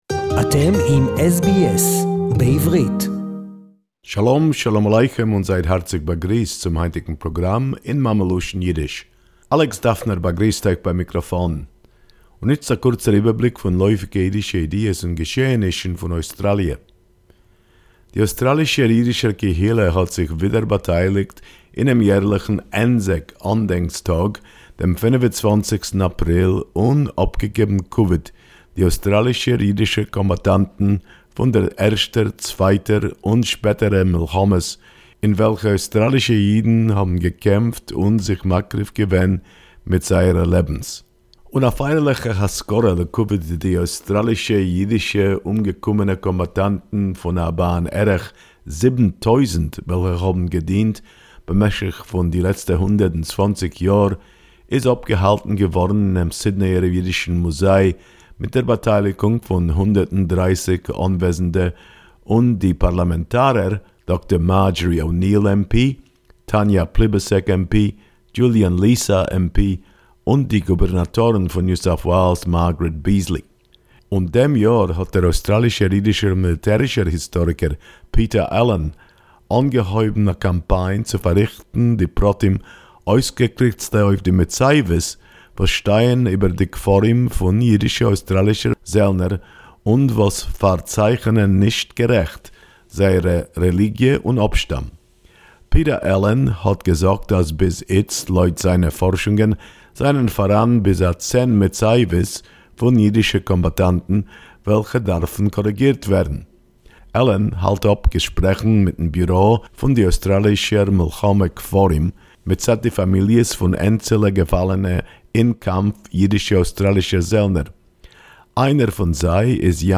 SBS Yiddish report